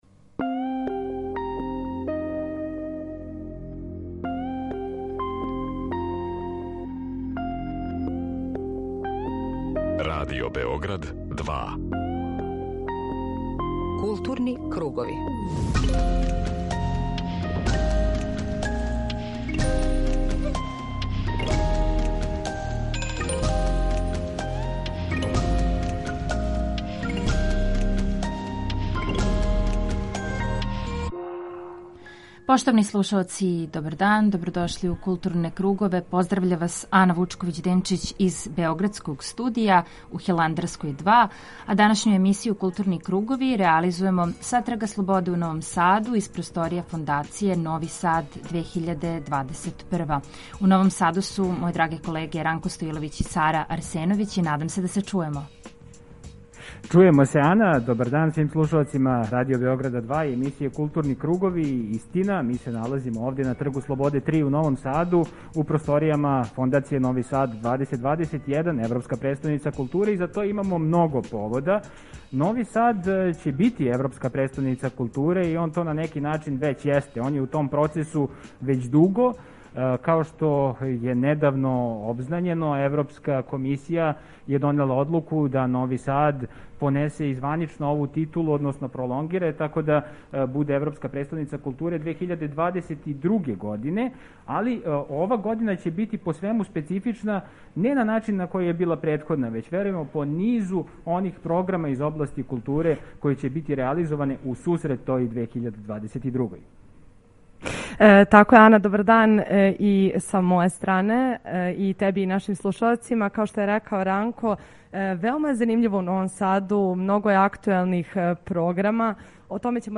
Данашњу емисију Културни кругови реализујемо са Трга слободе у Новом Саду, из просторија Фондације Нови Сад 2021 .